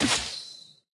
Media:Bo_baby_atk_1.wavMedia:Bo_base_atk_1.wav 攻击音效 atk 初级和经典及以上形态攻击音效
Bo_baby_atk_1.wav